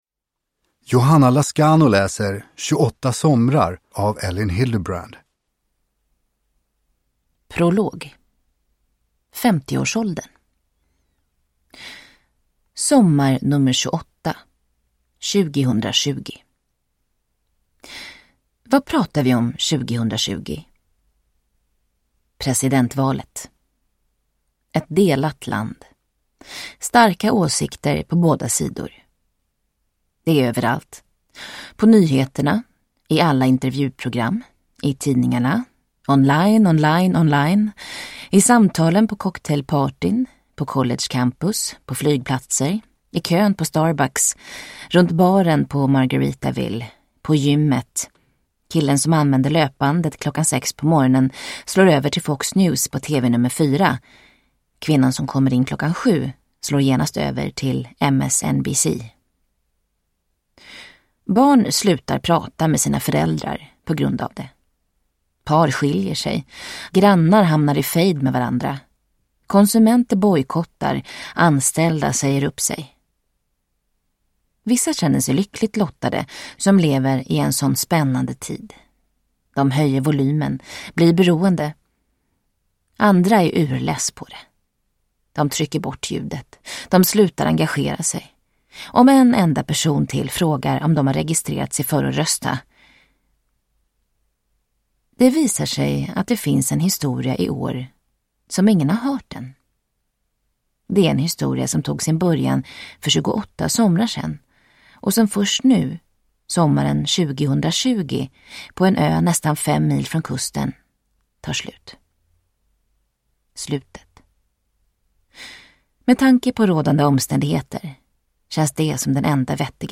28 somrar – Ljudbok – Laddas ner